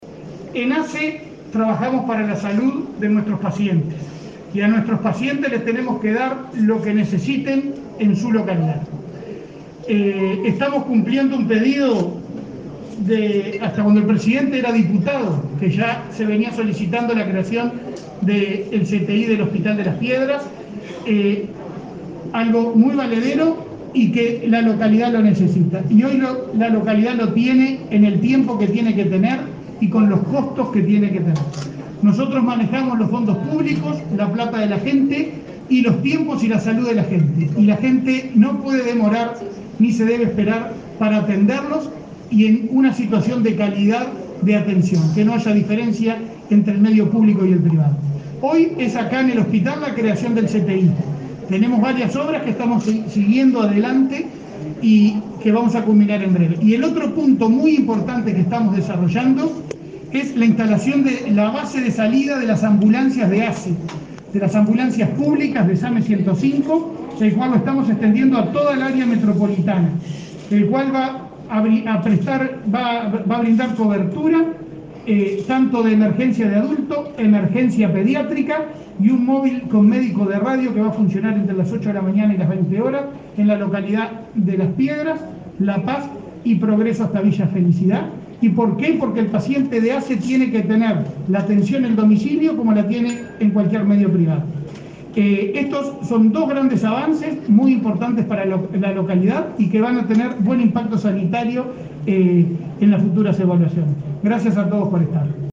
Palabras del presidente de ASSE, Leonardo Cipriani, en inauguración de CTI del hospital de Las Piedras
El jerarca hizo uso de la palabra en el acto de puesta en funcionamiento del nuevo servicio, el primero de este tipo, público, en el departamento de